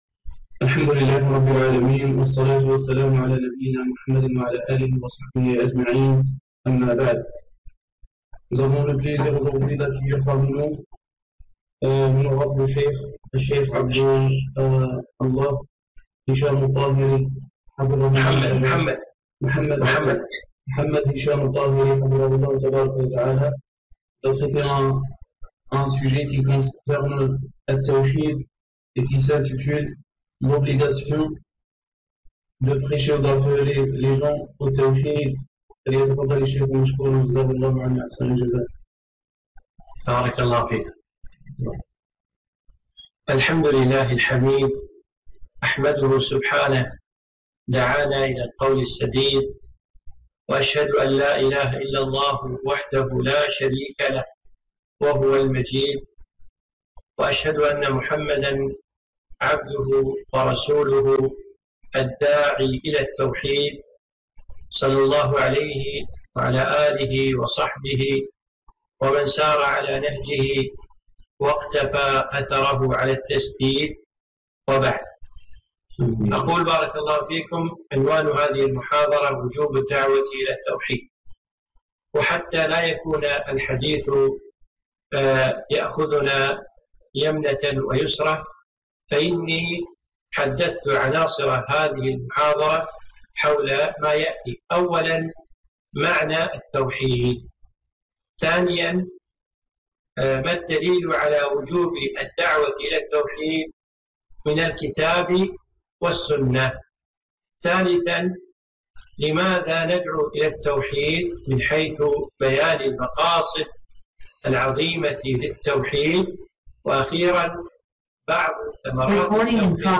محاضرة - وجوب الدعوة إلى التوحيد [ مترجمة للفرنسية